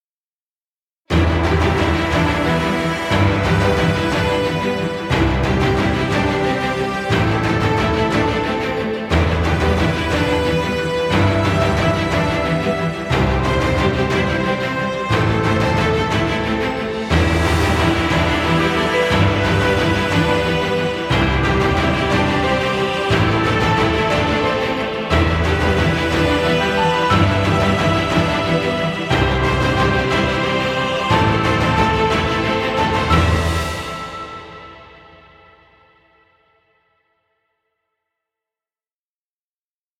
Epic cinematic music.